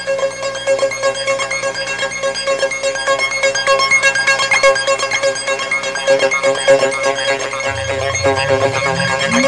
Squeaky Intro Sound Effect
Download a high-quality squeaky intro sound effect.
squeaky-intro.mp3